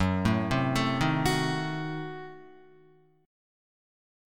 Gbdim7 Chord